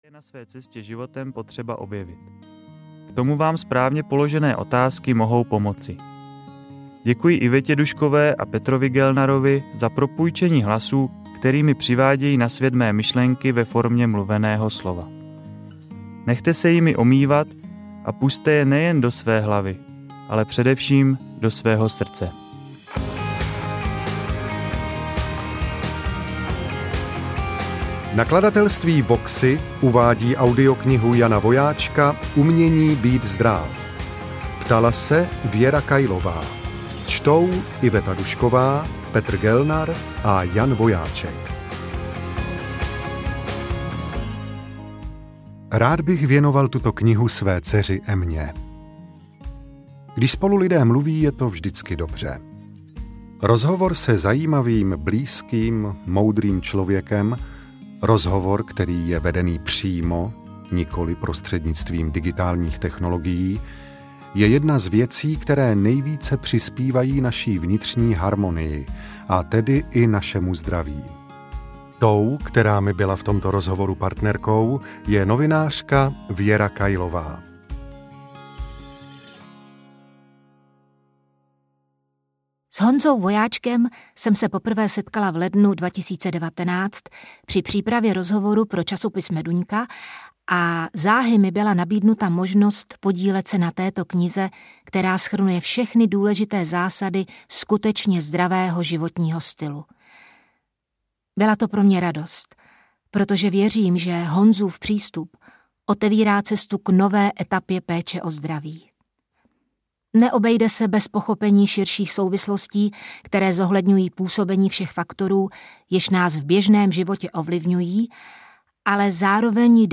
Umění být zdráv audiokniha
Ukázka z knihy